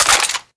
autocannon_pickup1.wav